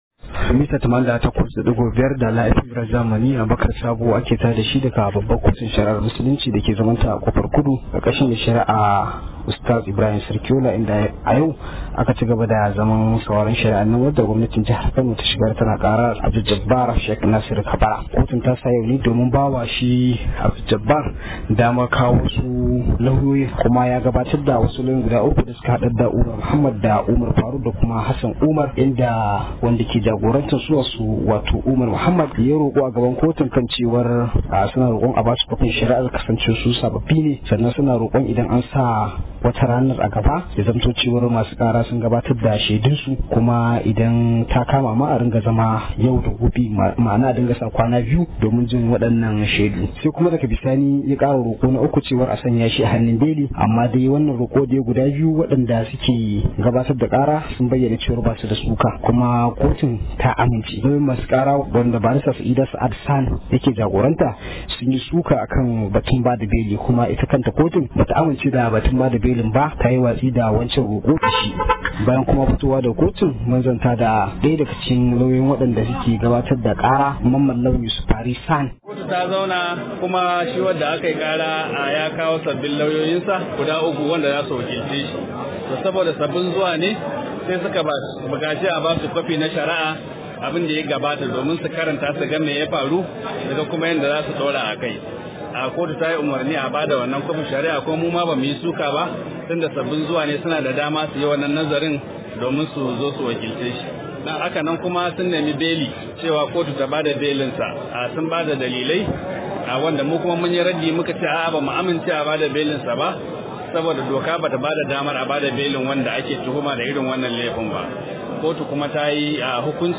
Rahoto: Mun ji dadin matsayar kotu – Lauyoyin Abduljabbar